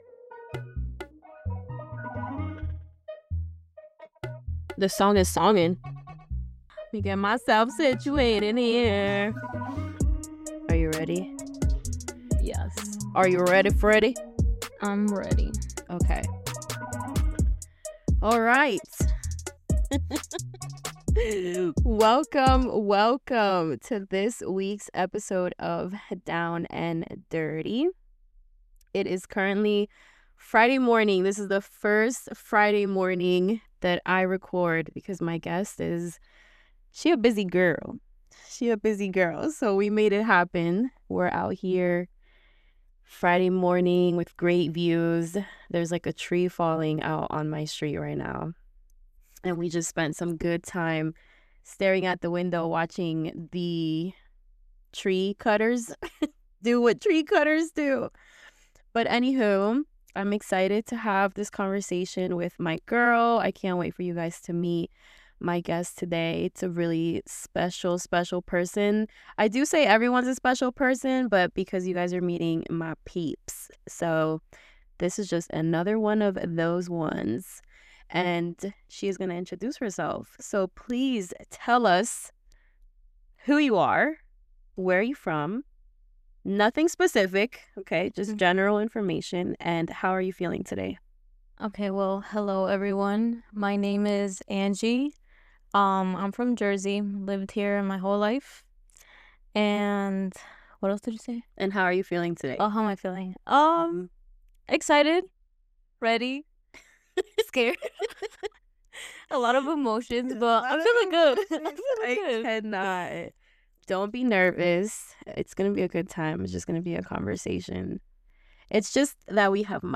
In this one, I open up about grieving friendships, taking control of your own life, selfishness, identity, politics, and God. No guests, no filter – just a girl venting.